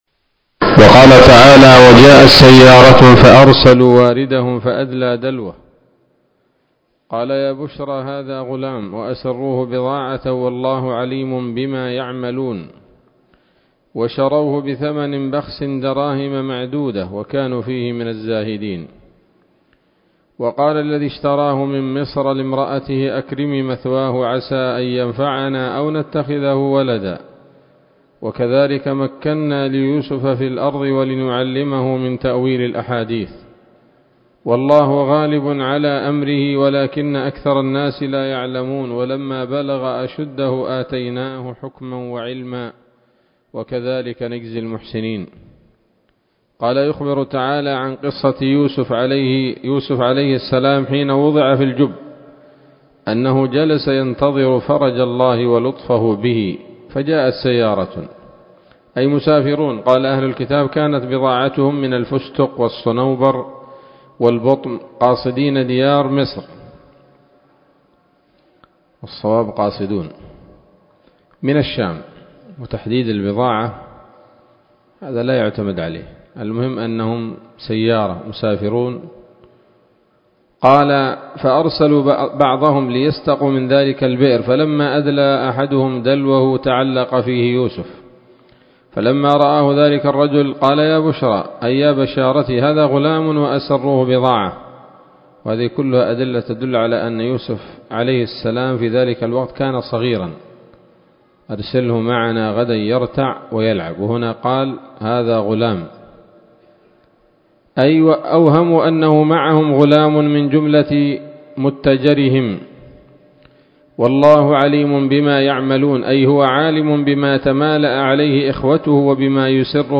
الدرس السابع والستون من قصص الأنبياء لابن كثير رحمه الله تعالى